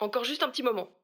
VO_ALL_Interjection_05.ogg